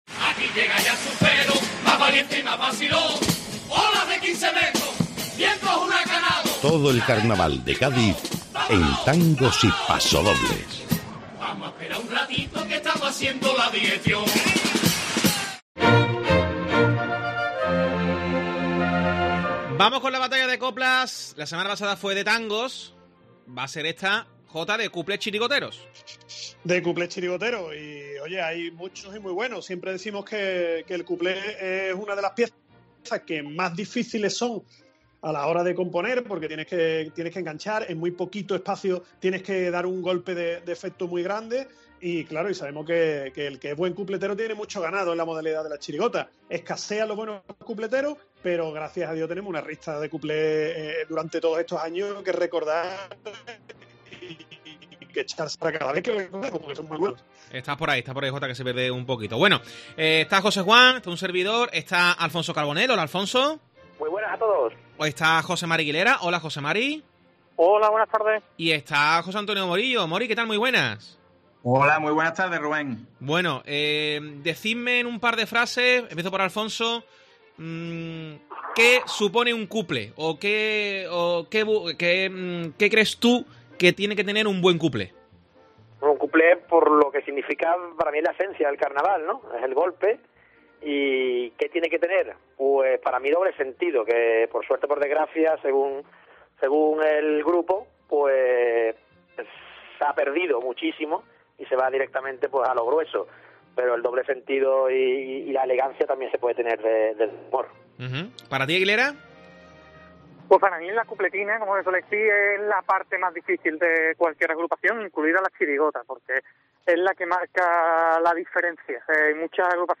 El cuplé es la gran pieza humorística del carnaval gaditano y en ella enfocan, sobre todo las chirigotas, toda su capacidad de humor e ironía